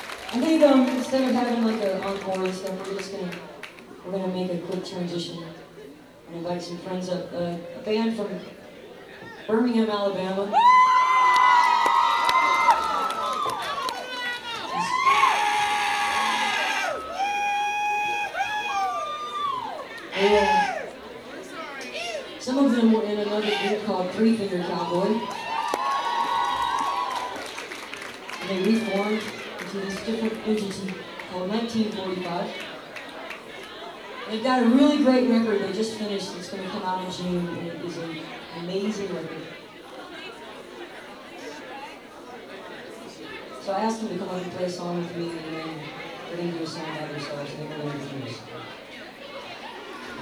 lifeblood: bootlegs: 2001-03-29: the echo lounge - atlanta, georgia (amy ray and the butchies)
12. talking with the crowd (0:50)